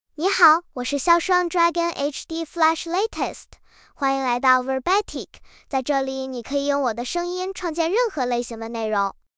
Xiaoshuang Dragon HDFlash LatestFemale Chinese AI voice
Voice: Xiaoshuang Dragon HDFlash LatestGender: FemaleLanguage: Chinese (Mandarin, Simplified)ID: xiaoshuang-dragon-hdflash-latest-zh-cn
Voice sample
Listen to Xiaoshuang Dragon HDFlash Latest's female Chinese voice.